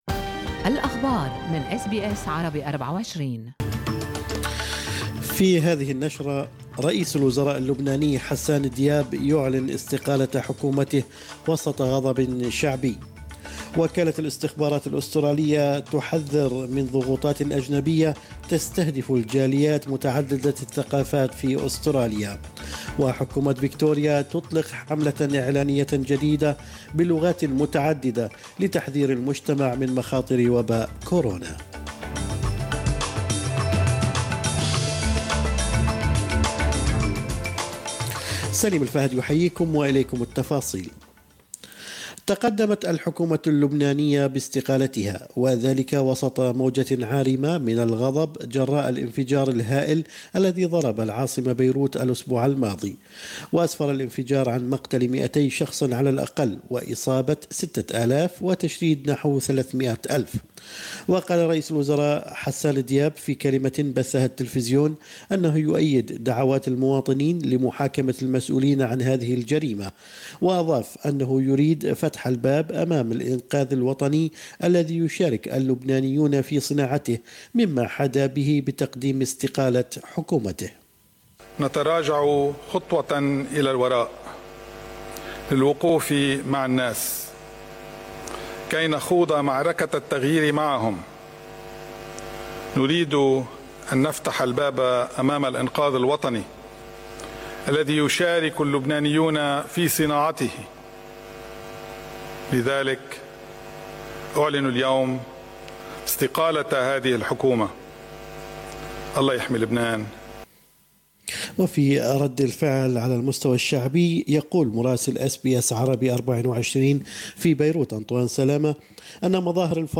نشرة أخبار الصباح 11/8/2020